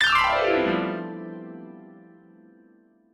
glissando1.ogg